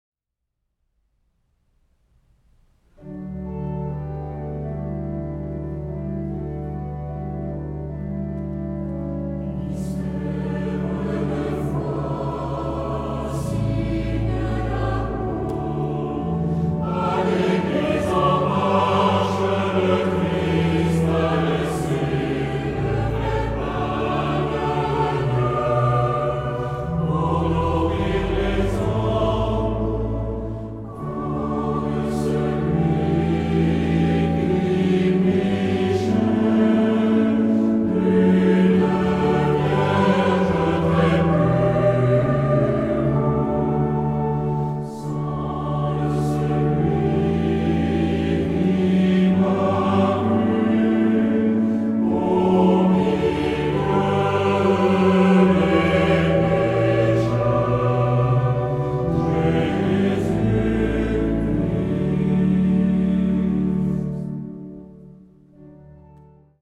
Genre-Style-Forme : Sacré ; Hymne (sacré)
Caractère de la pièce : calme
Type de choeur : SAH  (3 voix mixtes )
Instruments : Orgue (1)
Tonalité : ré majeur